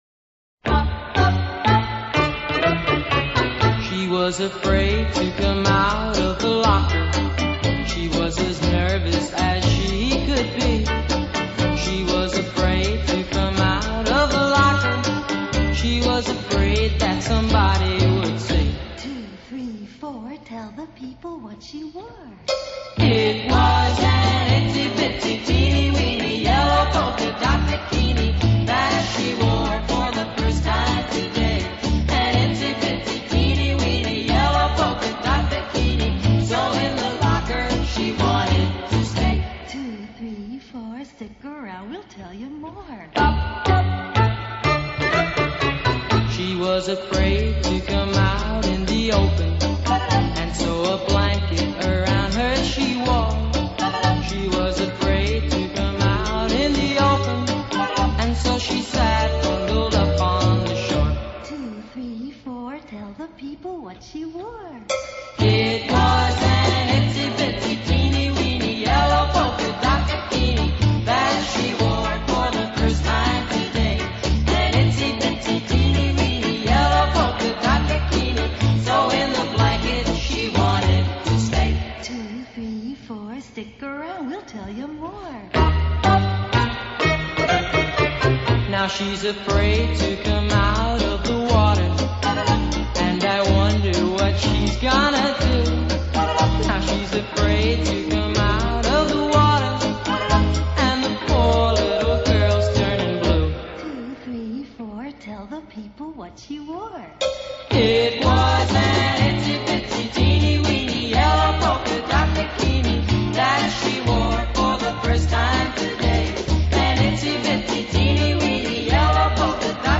Easy Listening, Oldies, Jazz | Publisher: n/a